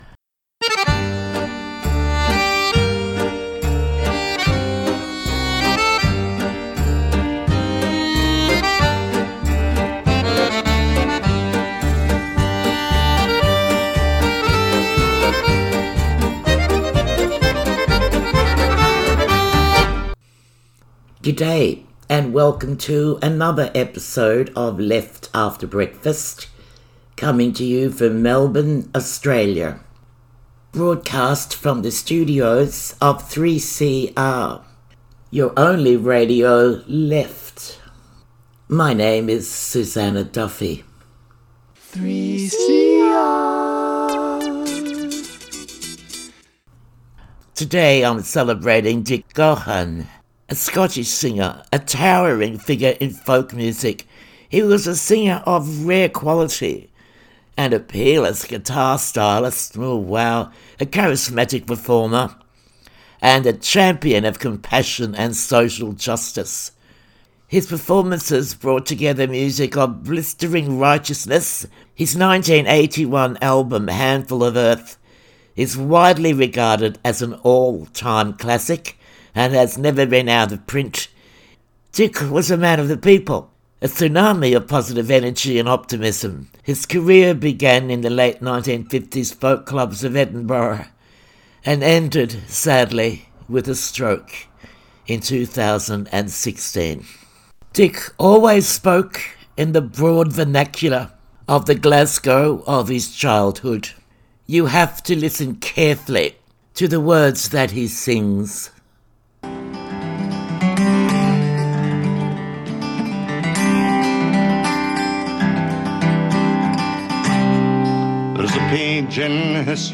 Best of the Left commentary and chat.